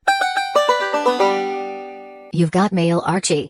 Do you like my email alert?